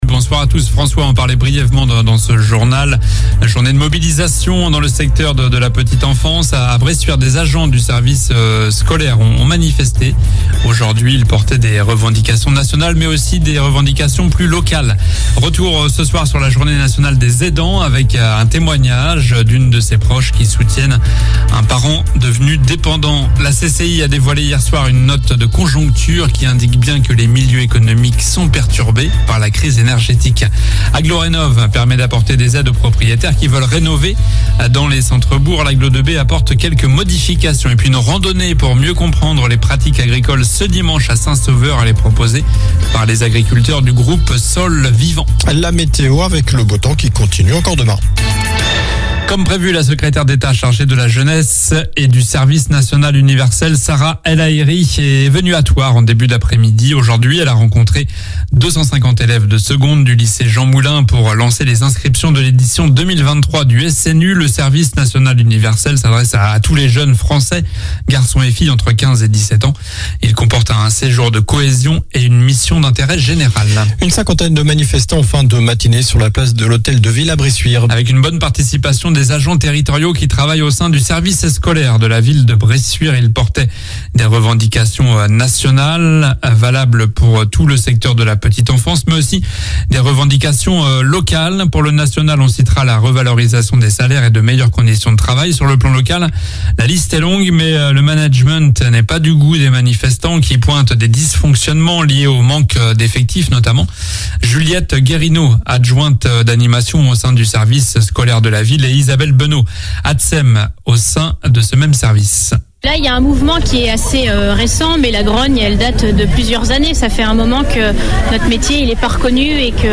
Journal du jeudi 06 octobre (soir)